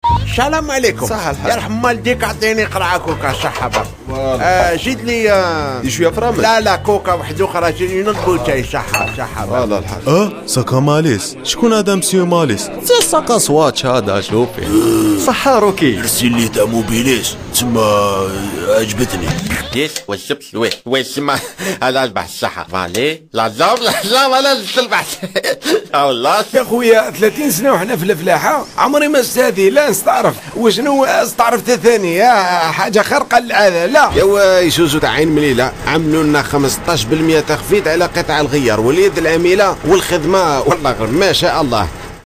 Comédien voix off en langue arabe, réalisateur radio, chanteur, imitateur depuis plus de 20 ans, je met chaque jour ma voix et mon expérience à votre service pour tout enregistrement de voix : pub radio ou TV, commentaire de film institutionnel, film d\'entreprise, habillage d\'antenne, audiotel, billboard, composition, cartoon, comédie radio, bande annonce, signature, attente téléphonique, jingle, voice over....
Sprechprobe: Industrie (Muttersprache):